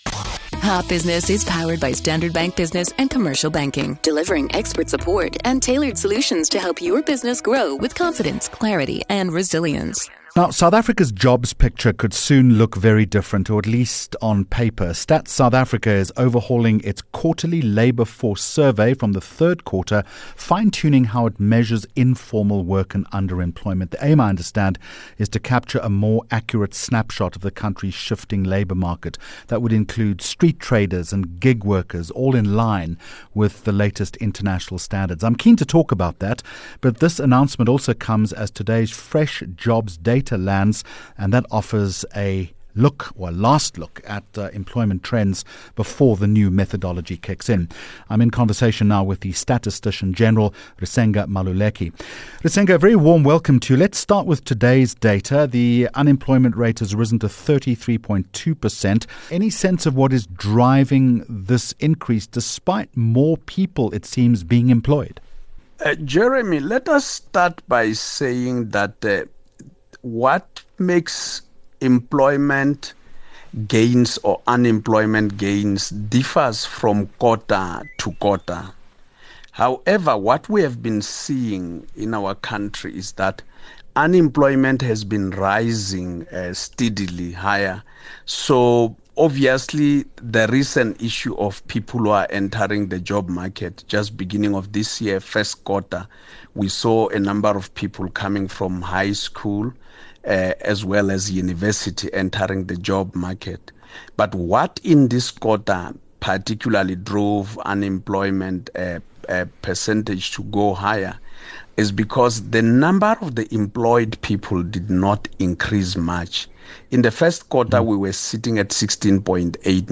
12 Aug Hot Business interview - Risenga Maluleke 12 August 2025